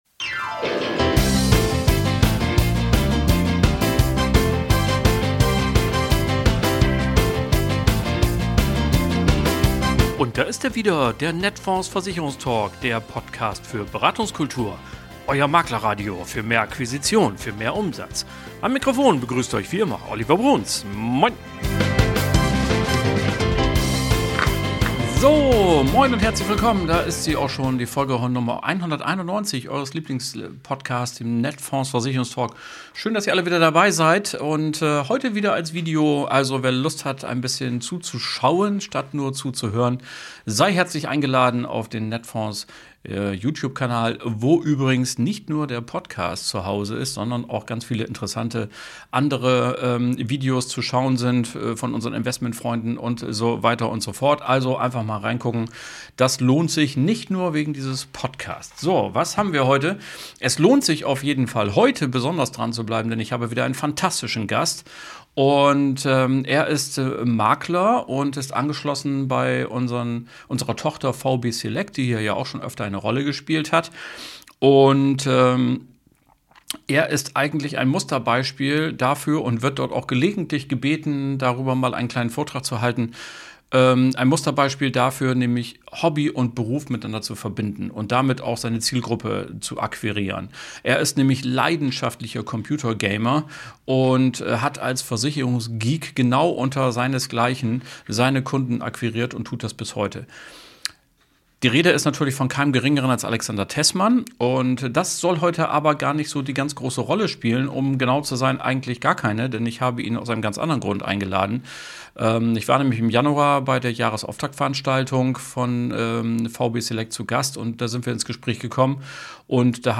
Hören Sie spannende Interviews und Reportagen mit praktischen Tipps oder vertiefenden Hintergrund-Informationen.